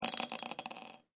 サイコロ・ダイス | 無料 BGM・効果音のフリー音源素材 | Springin’ Sound Stock
ガラスでチンチロリン1.mp3